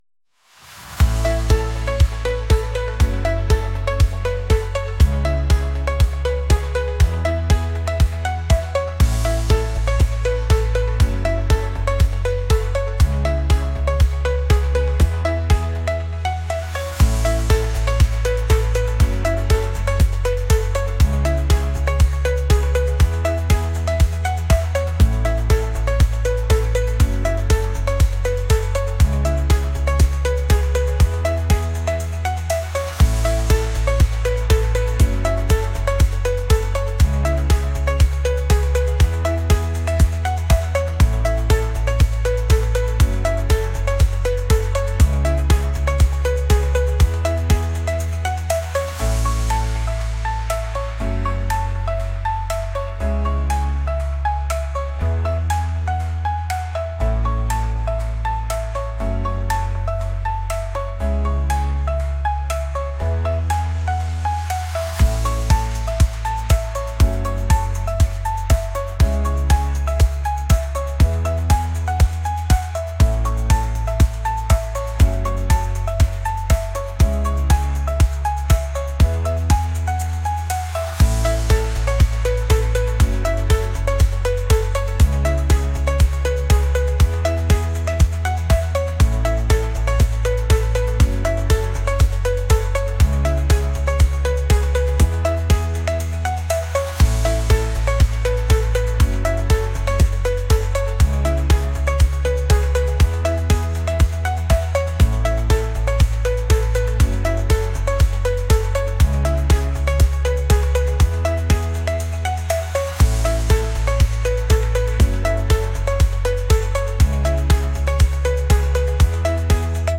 pop | electronic | acoustic